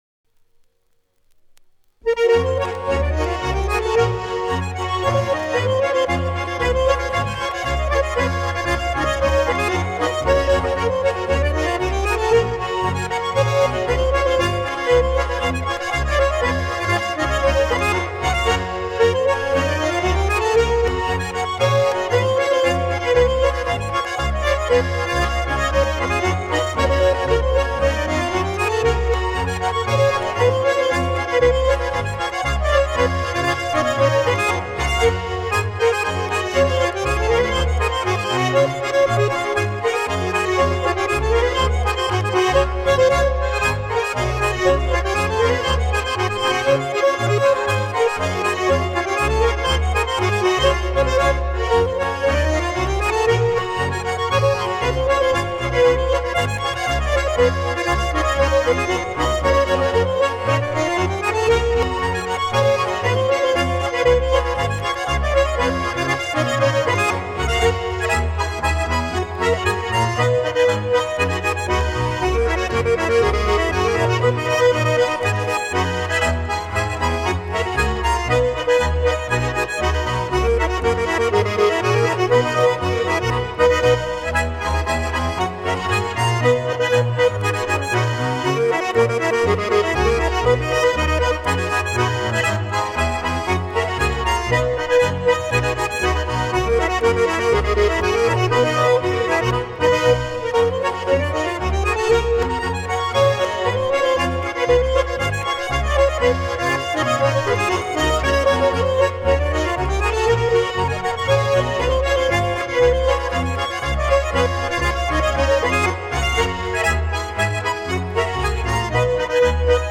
Schottisch